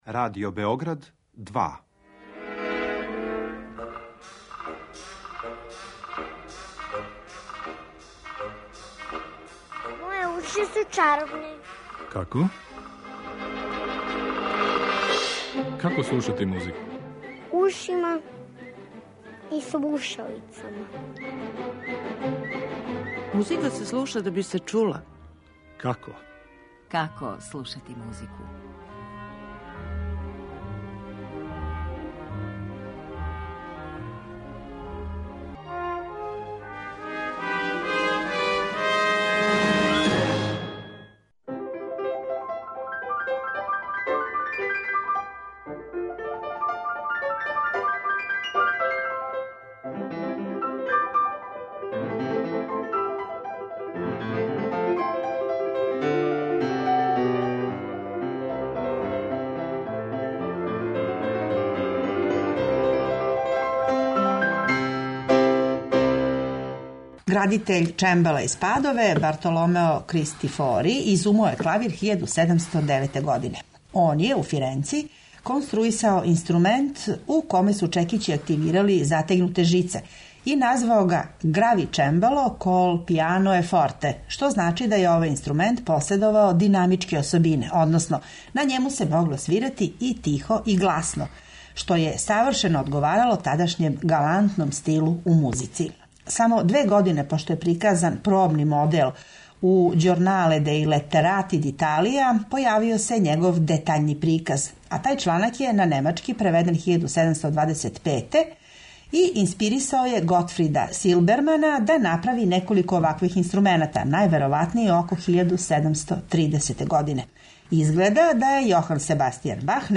Свака од емисија посвећена је по једној великој школи клавира: немачкој, француској, руској и енглеској, а слушаћете и одговарајуће интерпретације забележене на архивским снимцима које су остварили највећи представници ових школа.